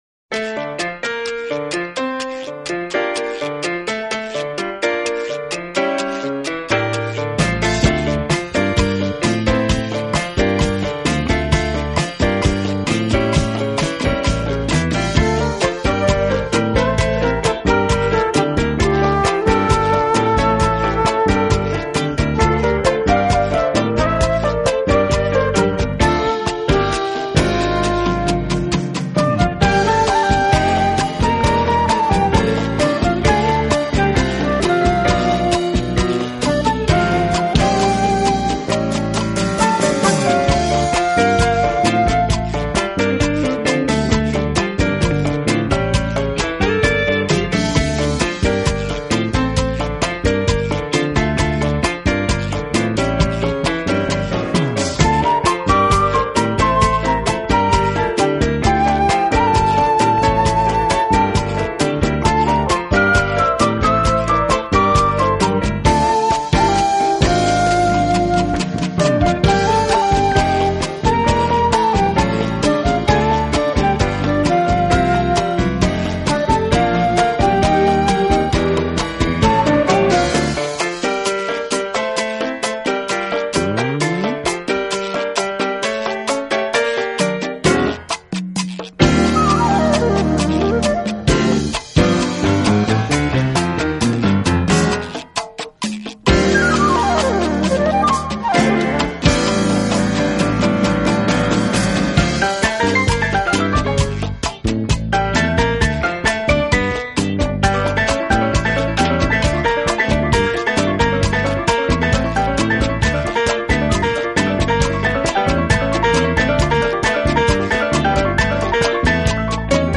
拉丁爵士的特点是在爵士乐的基础上融入了大量的打击乐器，